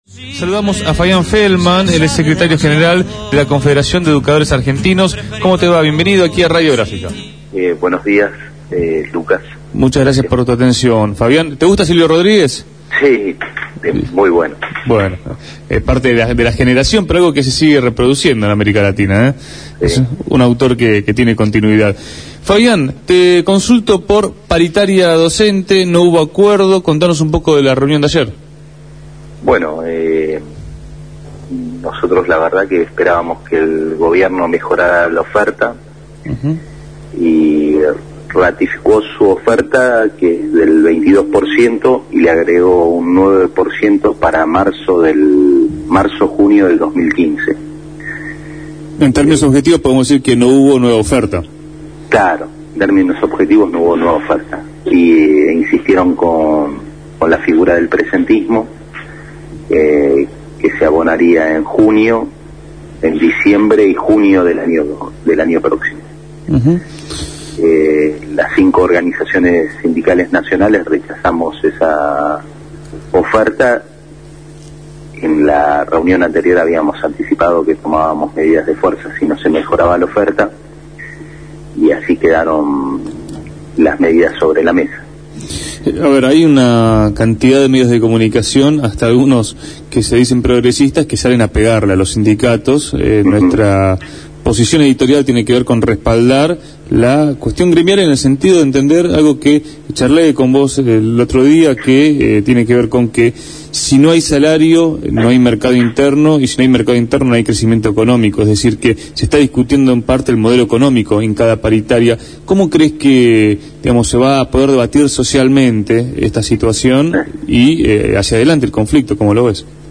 fue entrevistado en Punto de Partida sobre el tema.